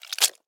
Звук, коли скорпіон жалить хвостом